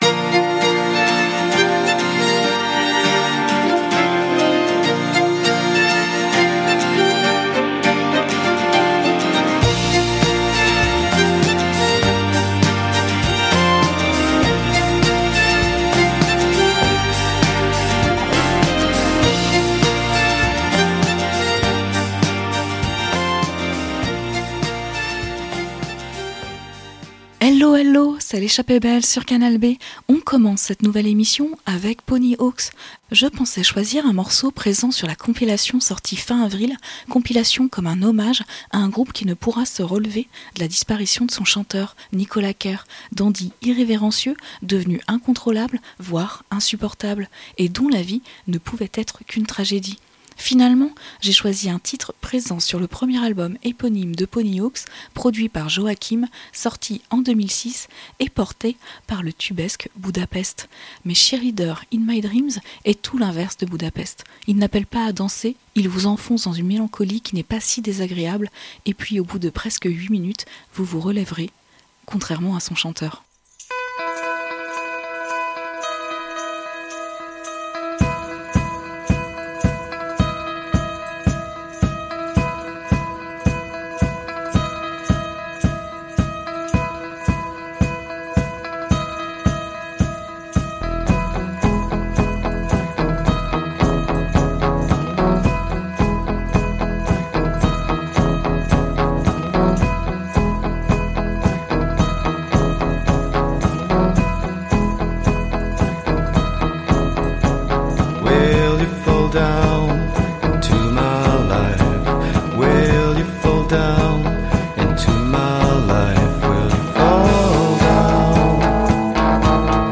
Remontez le son des 60's à nos jours en suivant les chemins folkeux, les grandes routes américaines, les ruelles popeuses, les chaussées de traviole et les avenues majestueuses.